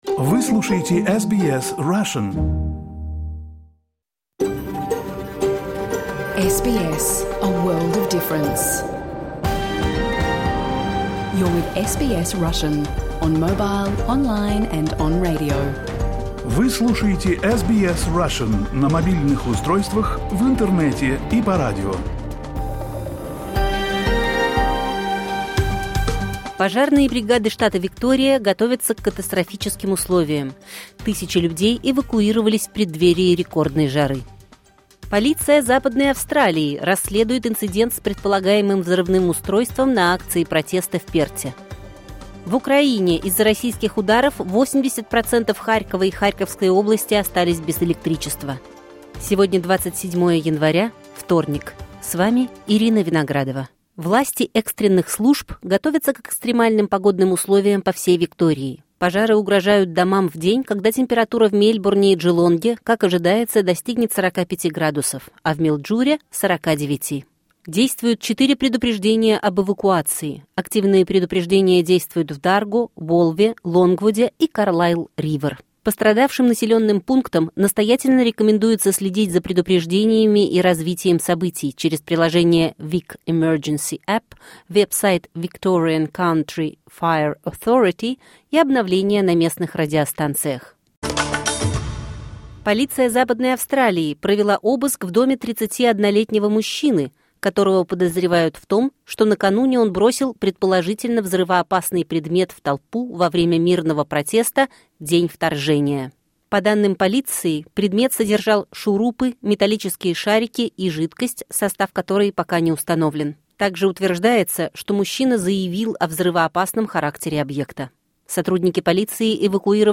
Новости SBS на русском языке — 27.01.2026